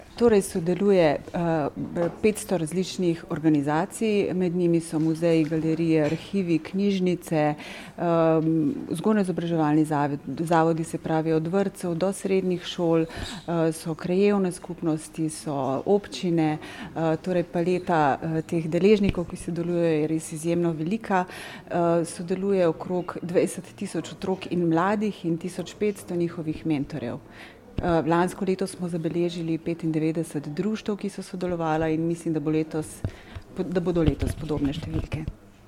izjava koliko je sodelujocih.mp3